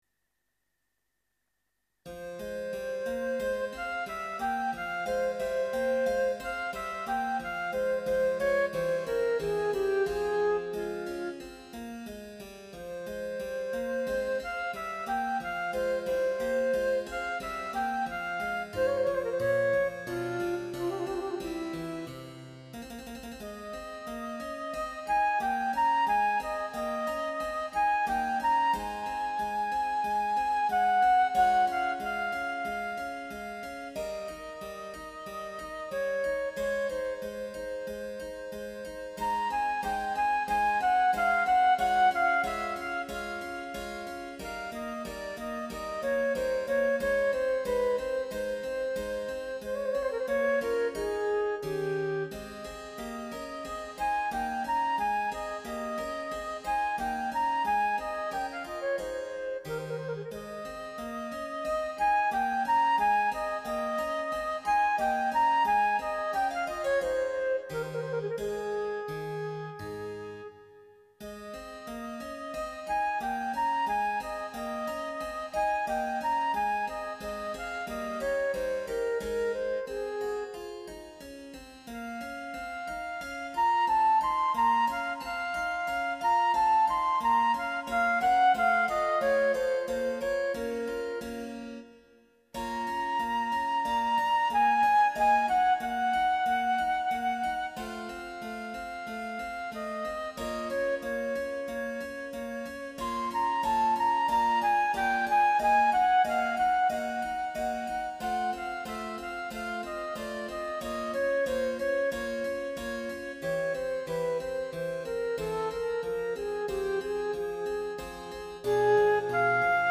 (Sonata for Flute and Harpsichord in F major, K.13)
第2楽章／アンダンテ (II. Andante)
MIDIデータ作成